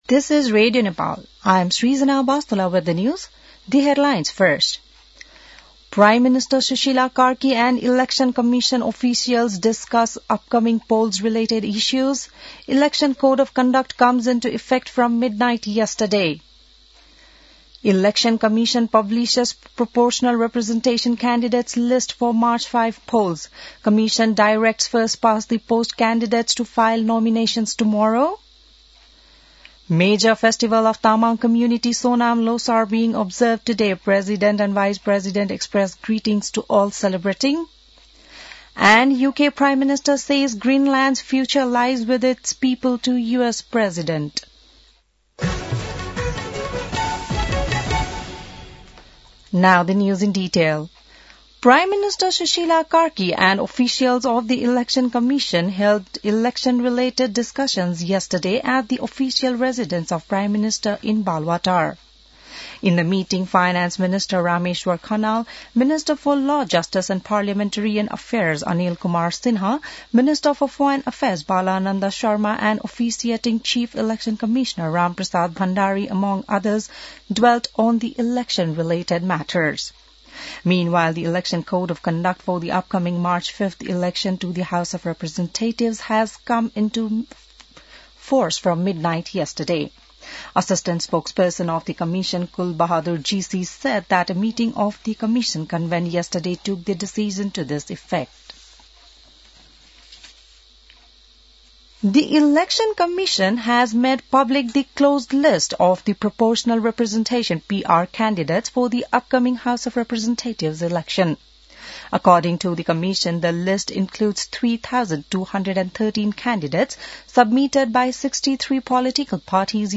बिहान ८ बजेको अङ्ग्रेजी समाचार : ५ माघ , २०८२